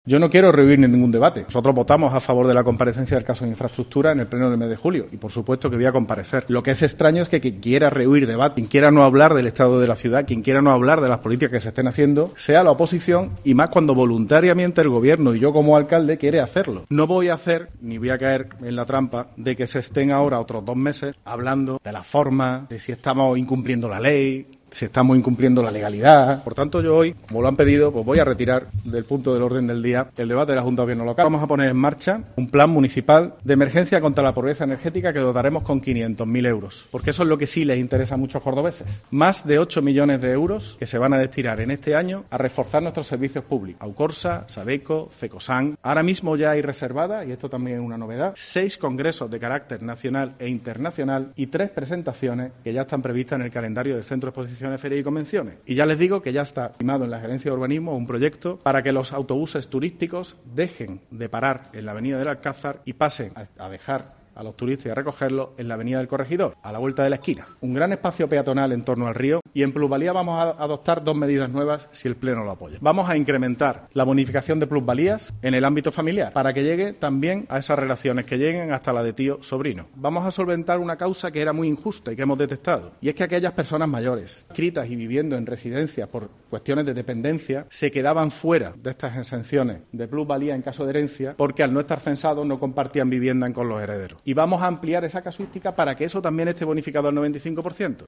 Escucha al alcalde de Córdoba, José María Bellido, en los momentos previos al Pleno